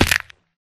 damage
fallbig2.ogg